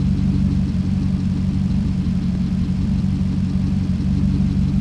v8_14_idle.wav